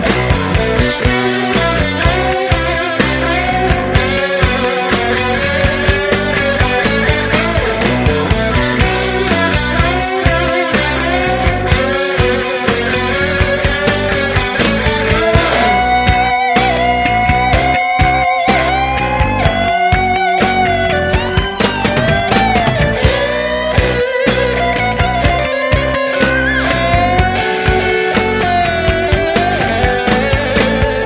• Качество: 320, Stereo
гитара
без слов
инструментальные
Гитарное соло